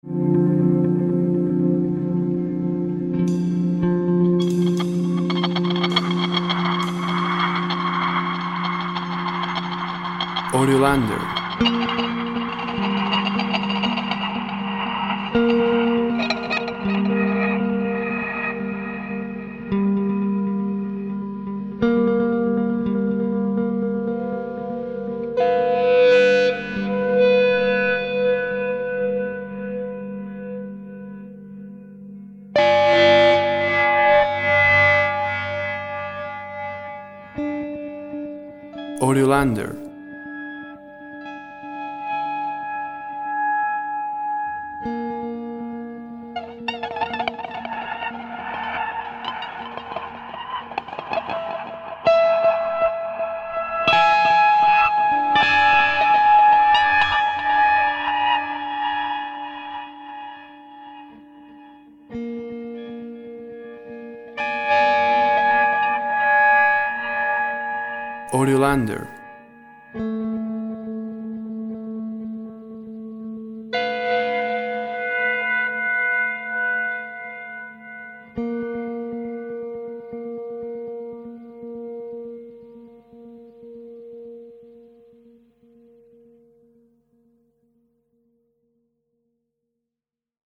WAV Sample Rate 16-Bit Stereo, 44.1 kHz
Tempo (BPM) 85